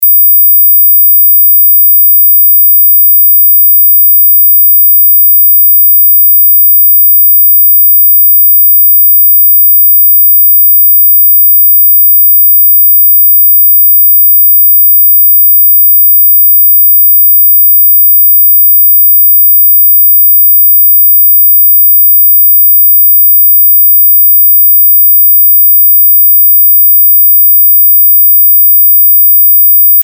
el_tono_del_mosq.mp3